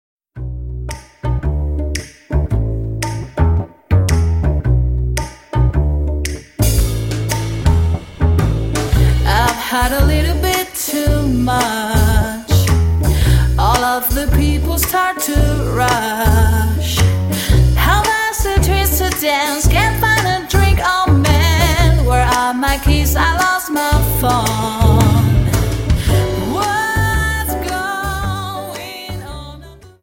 Dance: Slowfox 29